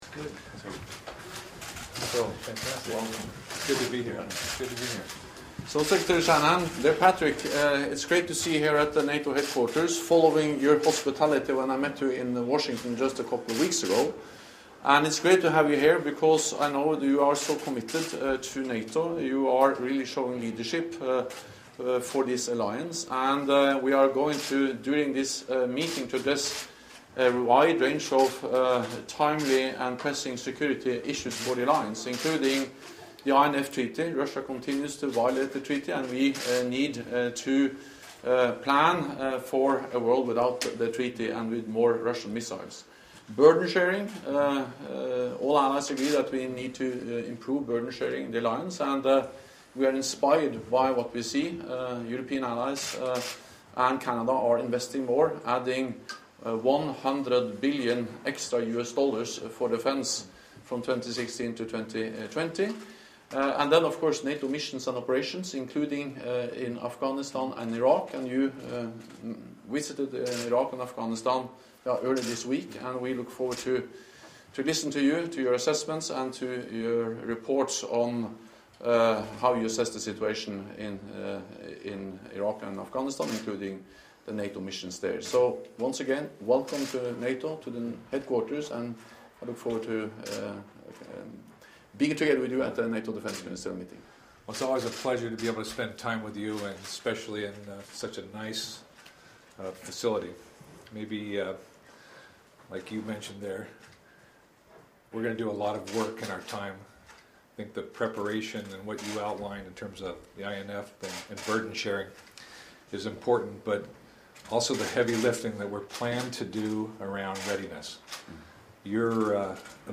Remarks
by NATO Secretary General Jens Stoltenberg on meeting with US Acting Secretary of Defense Patrick M. Shanahan